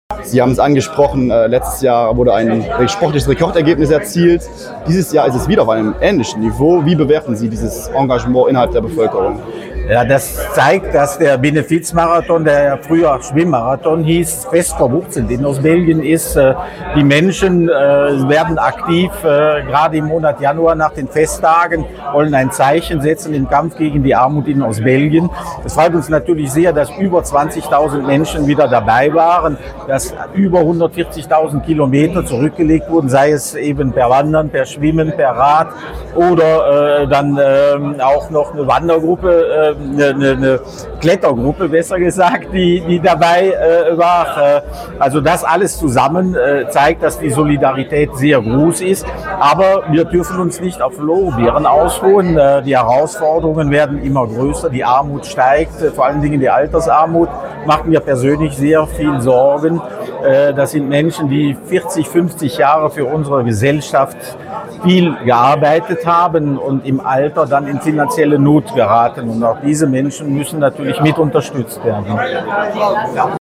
sprach mit